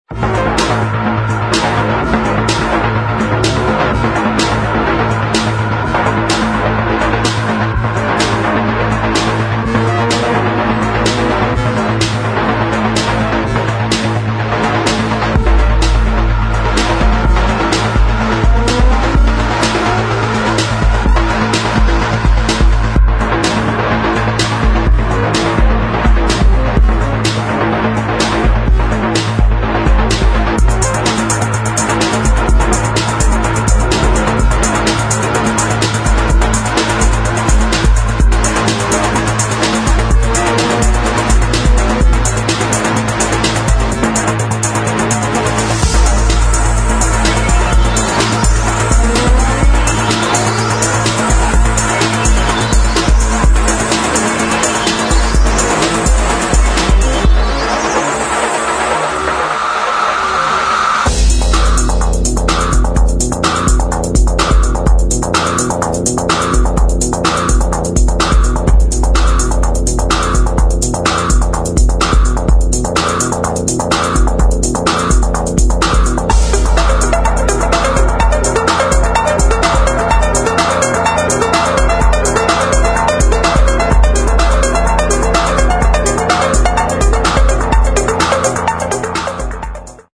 [ ELECTRO ]